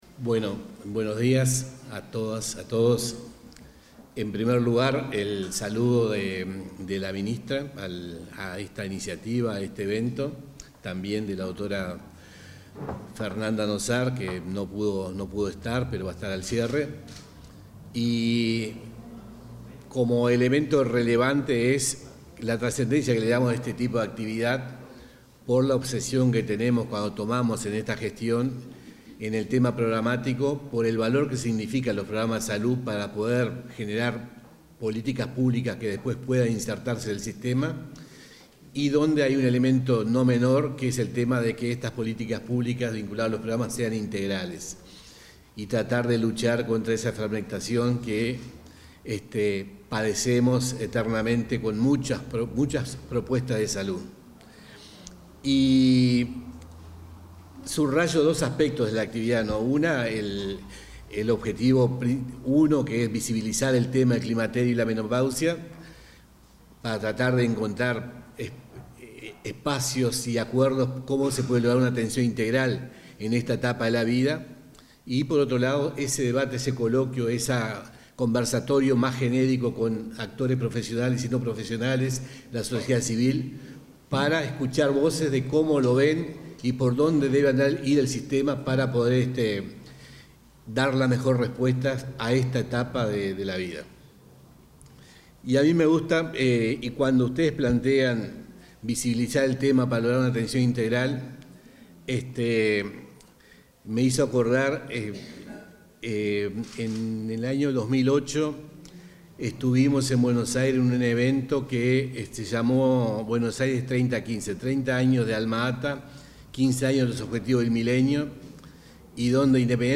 Palabras del subdirector general del MSP, Gilberto Ríos